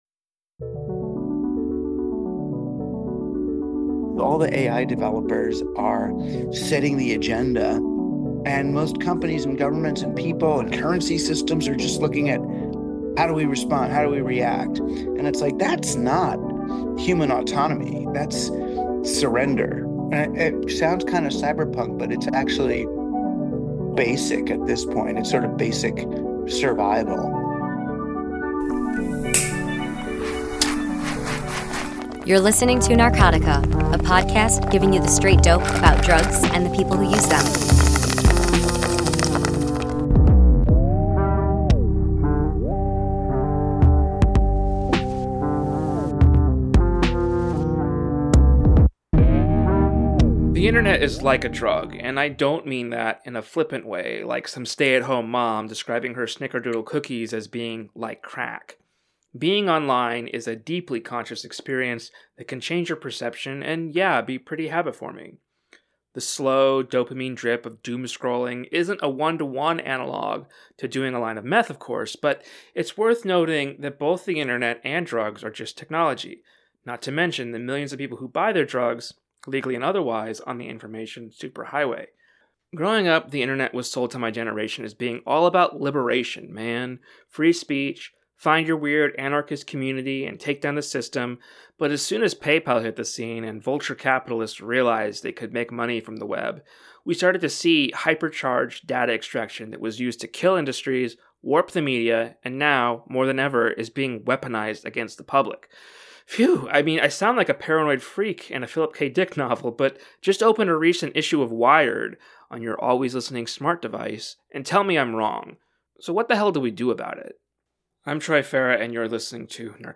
interviews Douglas Rushkoff, an author of some 20 books, a mass media theorist, and so much more.